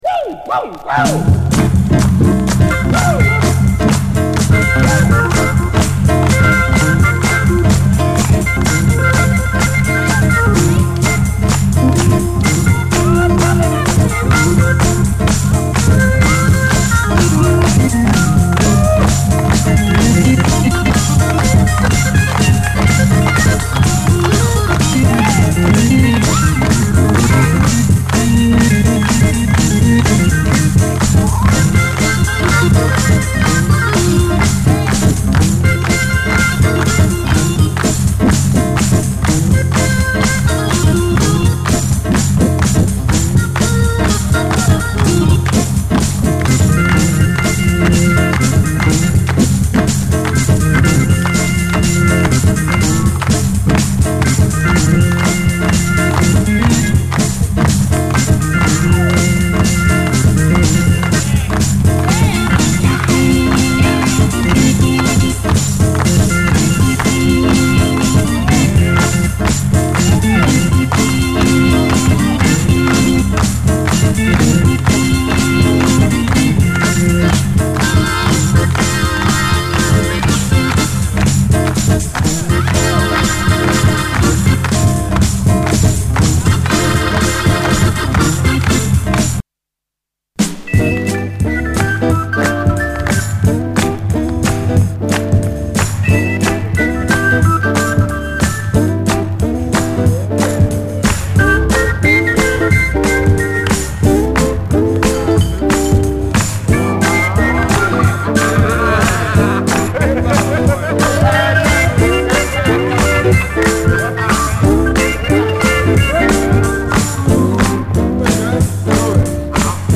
SOUL, 60's SOUL, JAZZ FUNK / SOUL JAZZ, JAZZ, 7INCH
レア・モッド・オルガン・ソウル・ジャズ45！